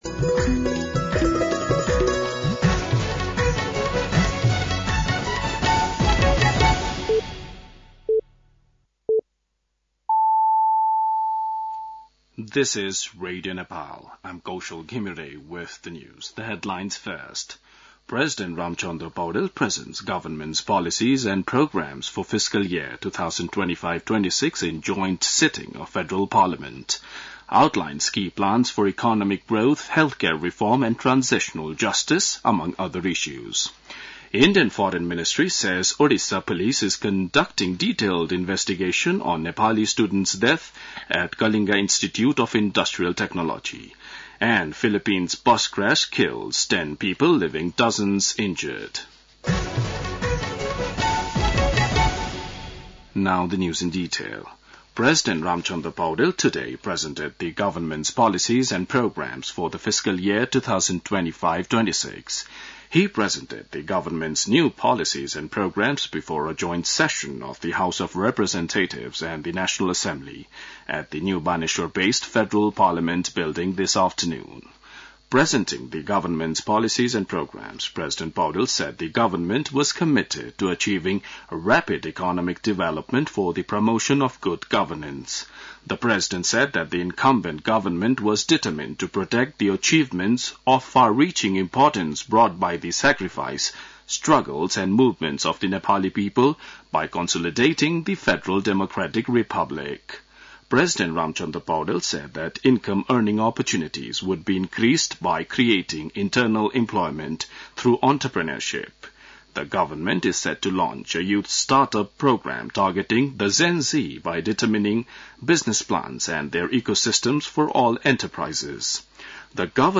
बेलुकी ८ बजेको अङ्ग्रेजी समाचार : १९ वैशाख , २०८२